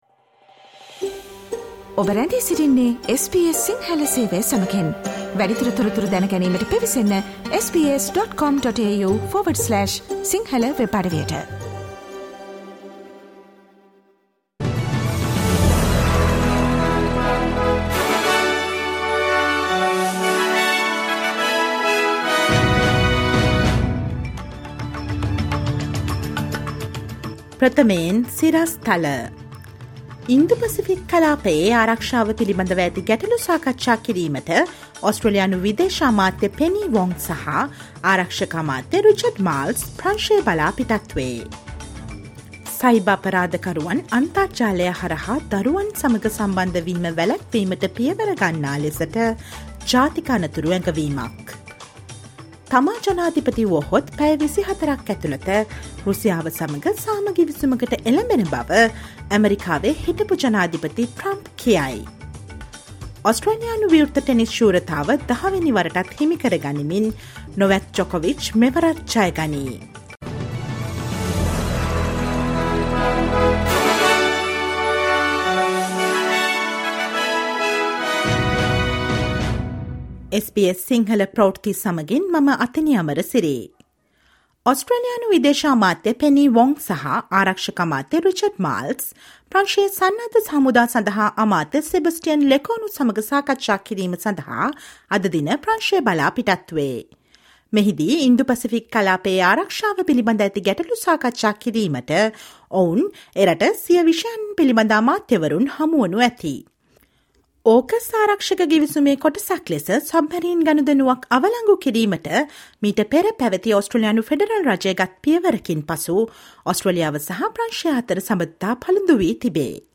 ඔස්ට්‍රේලියාවේ නවතම පුවත් , විදෙස් පුවත් සහ ක්‍රීඩා පුවත් රැගත් SBS සිංහල සේවයේ 2023 ජනවාරි 30 වෙනිදා ප්‍රවෘත්ති ප්‍රකාශයට සවන් දෙන්න.